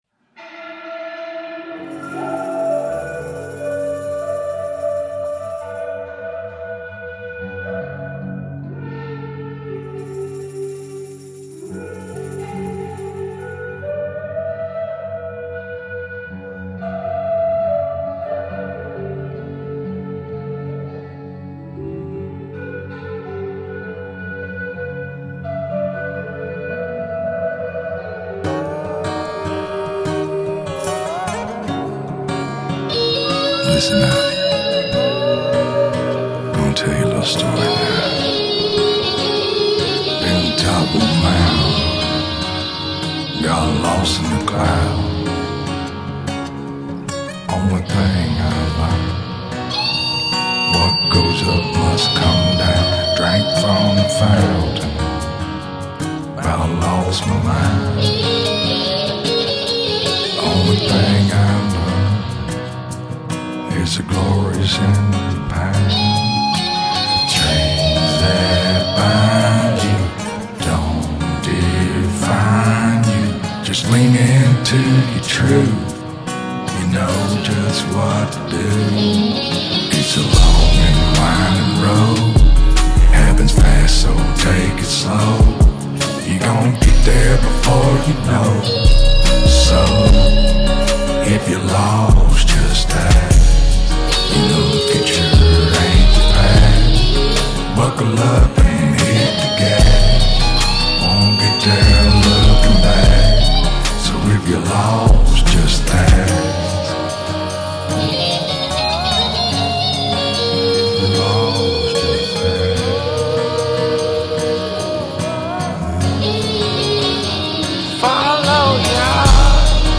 • Жанр: Blues